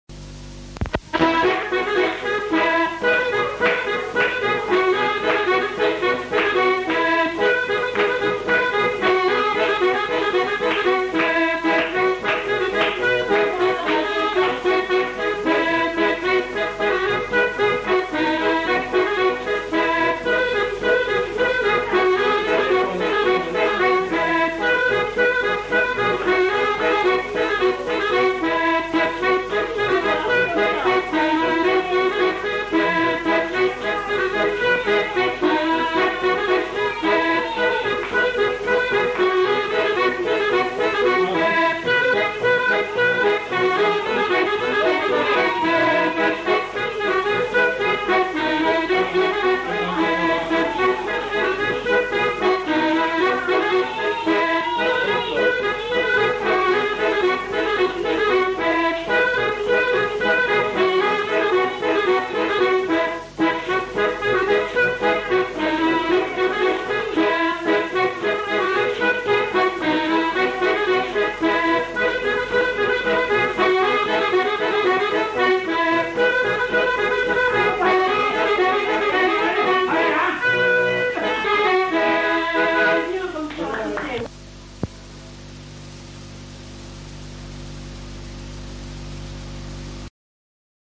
Aire culturelle : Grandes-Landes
Lieu : Luxey
Genre : morceau instrumental
Instrument de musique : accordéon diatonique
Danse : gigue